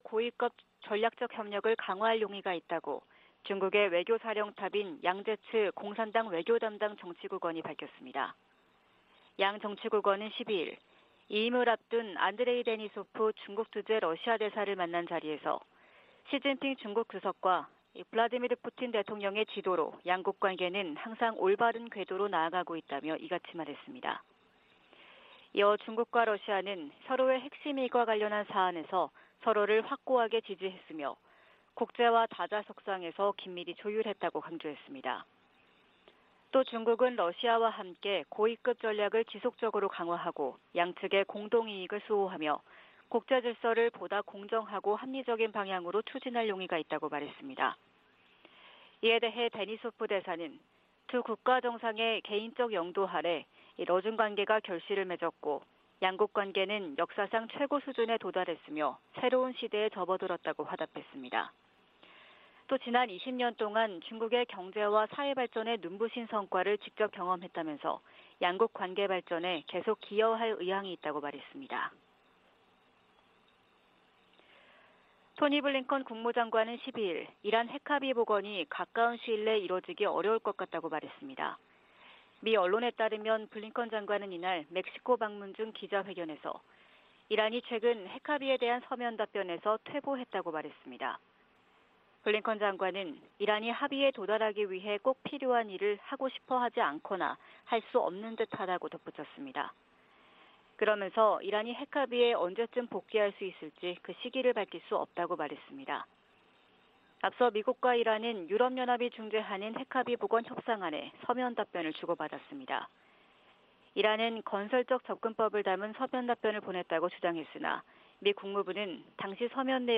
VOA 한국어 '출발 뉴스 쇼', 2022년 9월 14일 방송입니다. 미 국무부는 최근 북한과 중국 항구에서 수상한 움직임이 잇따라 포착되는 데 대해 제재 이행을 거듭 촉구했습니다. 북한 풍계리 핵실험장 3번 갱도에서 핵실험 준비 정황이 계속 관찰되고 있다고 국제원자력기구(IAEA) 사무총장이 밝혔습니다.